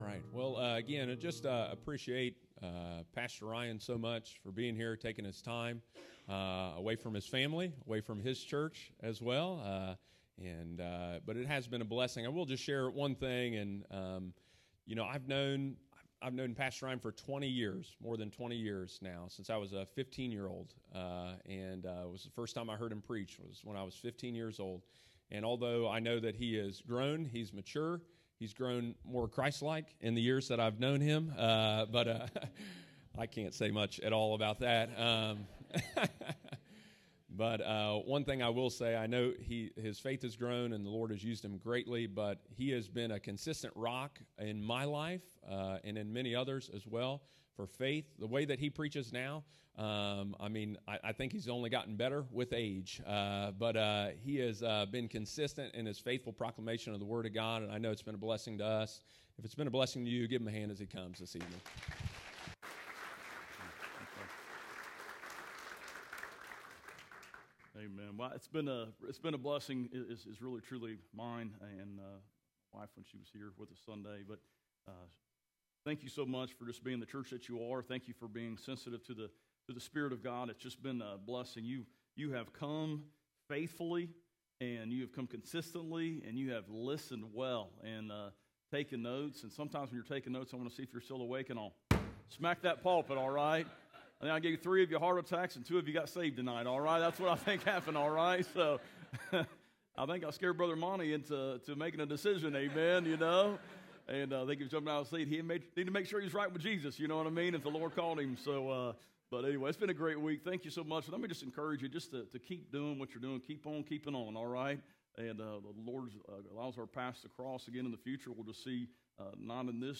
preaches revival out of the book of Revelation on Wednesday evening